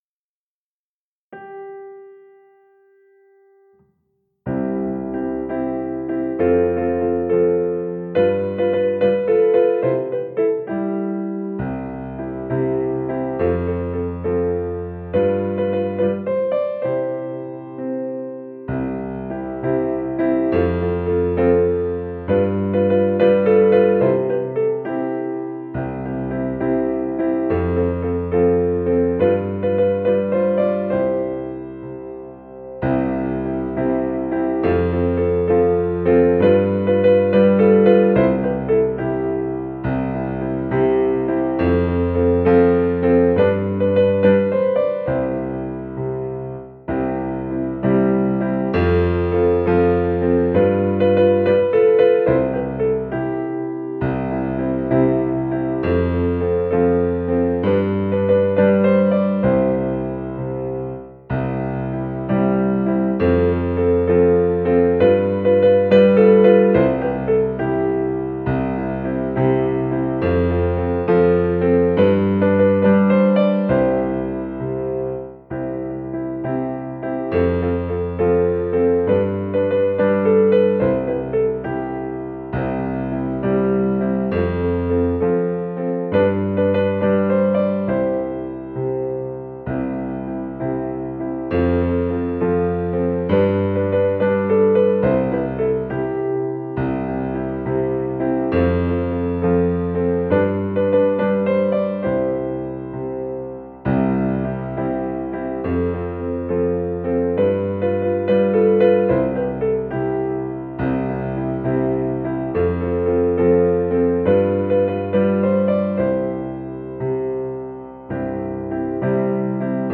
a) Text: Gannett, Melodie: O.Z.A. Hanish, Lied-Erstv. in D 1925, in US 1916